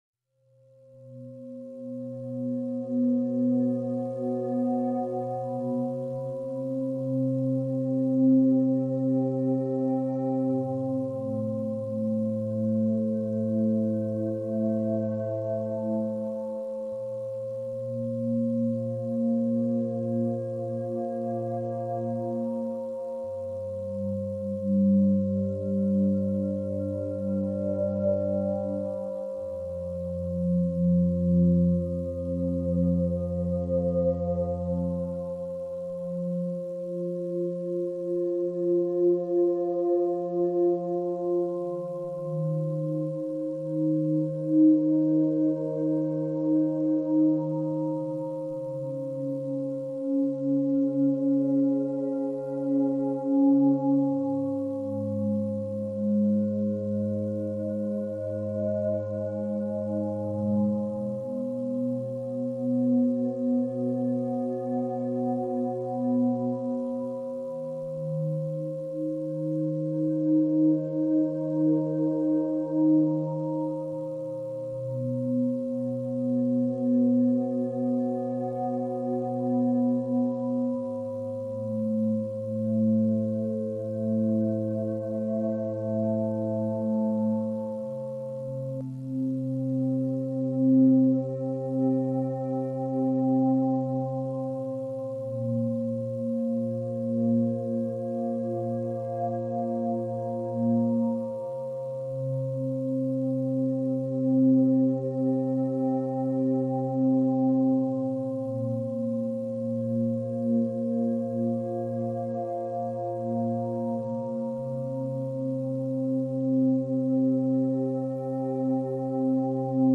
MI – 528 Hz – Frecuenica para la transformación y los milagros. Reparación del ADN